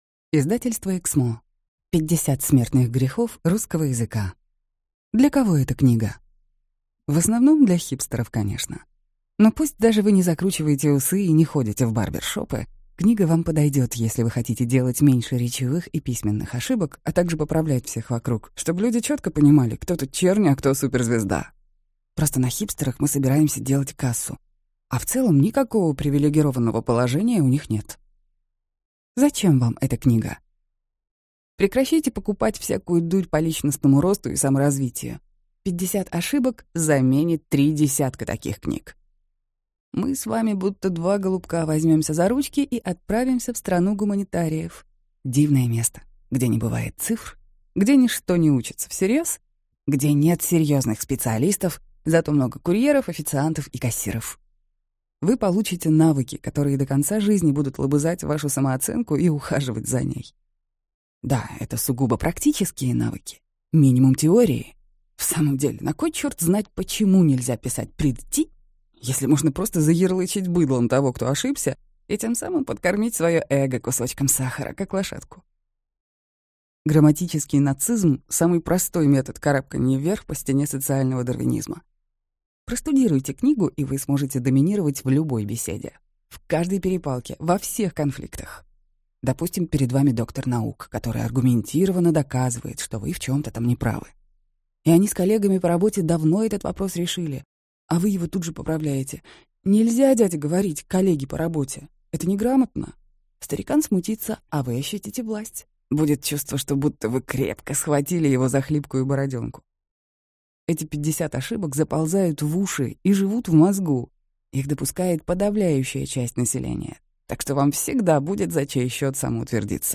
Аудиокнига 50 смертных грехов в русском языке. Говори и пиши правильно | Библиотека аудиокниг